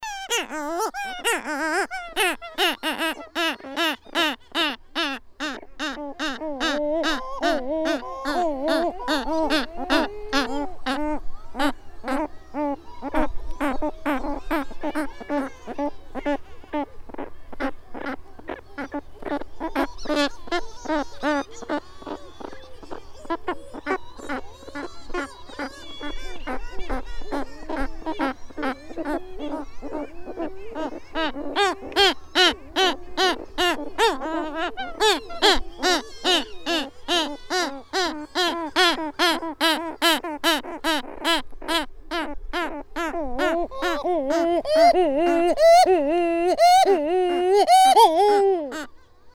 Calonectris edwardsii - Pardela de Cabo Verde.wav